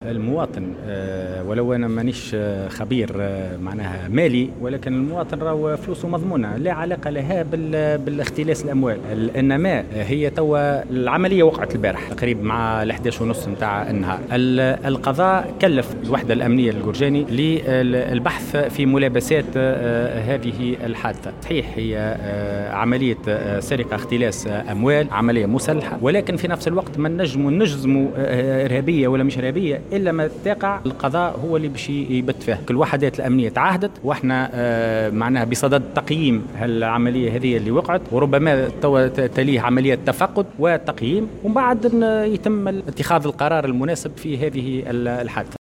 وأضاف في تصريح لـ"الجوهرة أف أم" على هامش زيارة عمل أداها اليوم إلى ولاية سوسة أن الابحاث جارية وأنه تم تكليف الوحدات الأمنية بالقرجاني للبحث في ملابسات الحادثة.